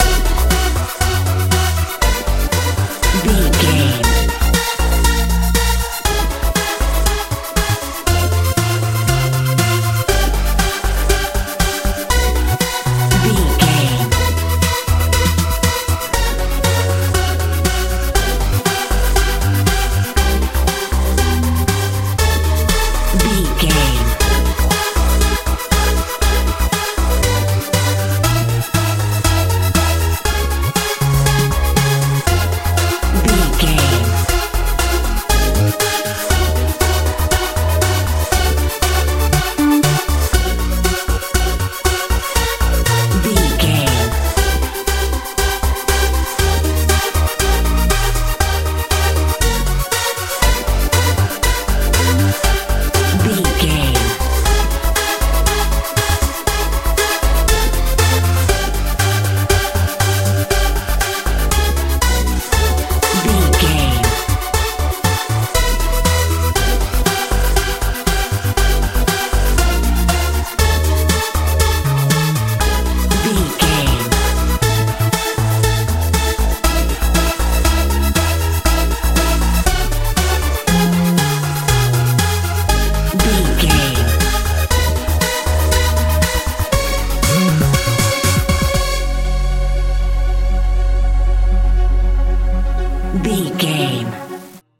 techno feel
Ionian/Major
bright
searching
synthesiser
bass guitar
drums
strange